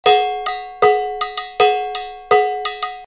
LP Salsa Sergio Bongo Cowbell
It delivers a deeper pitch and lively overtone associated with Latin music.
They offer livelier sounds than other drier LP bells and have become extremely popular.
Videos and Sound Clips LP ES10 Sound Sample 1 Customer Reviews Write a review Great deep tone Comments: This is a great product.